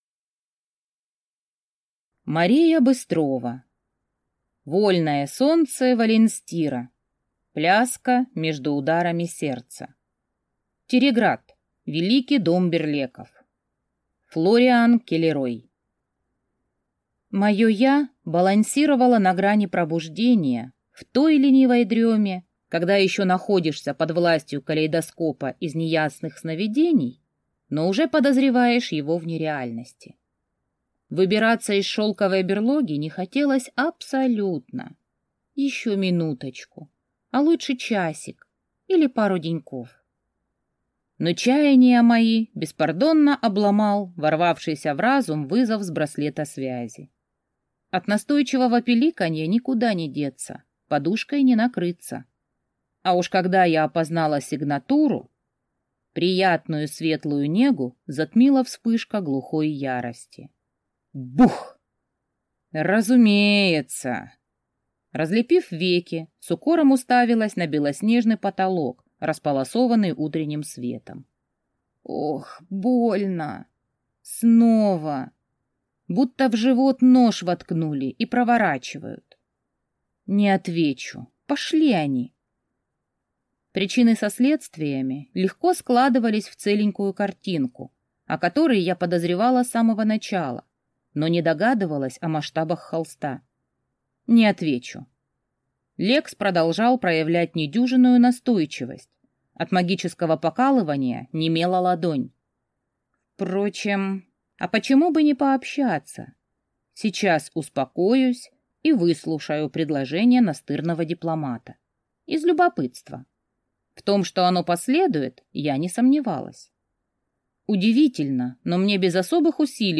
Аудиокнига Вольное солнце Воленстира. Пляска между ударами сердца | Библиотека аудиокниг